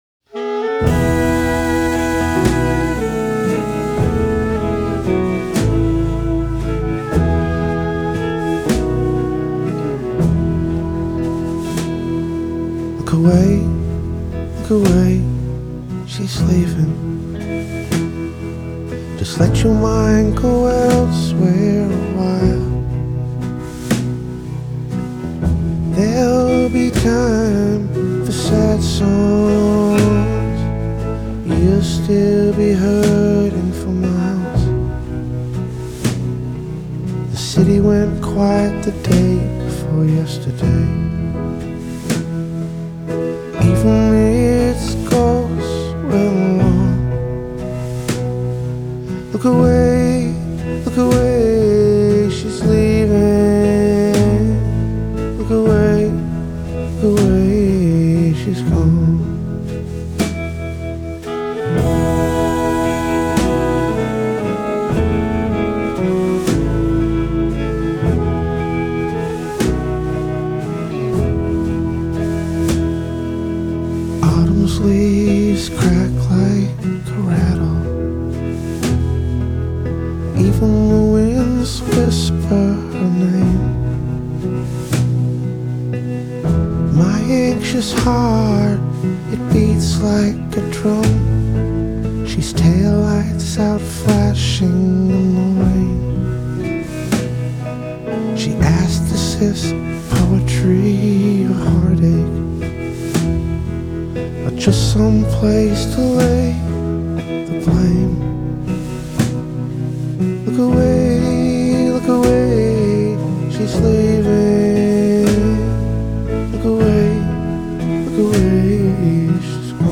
guitar/vocals
saxophone
guitar
piano
bass
drums